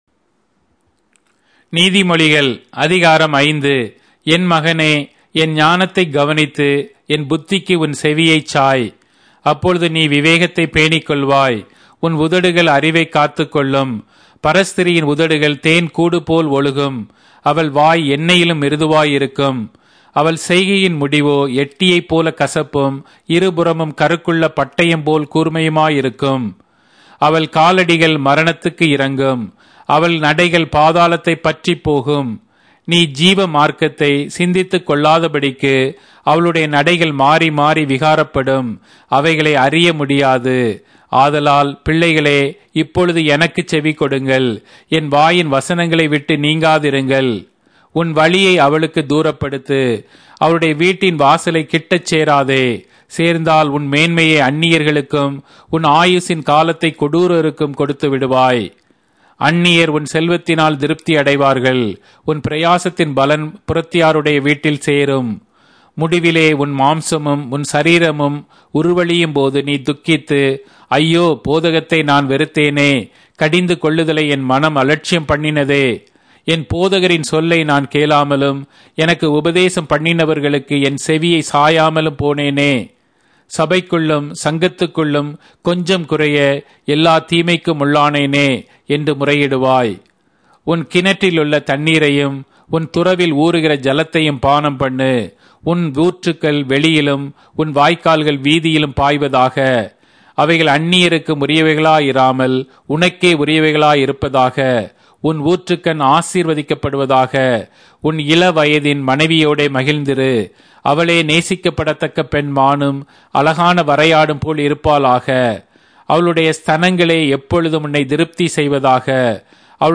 Tamil Audio Bible - Proverbs 11 in Rv bible version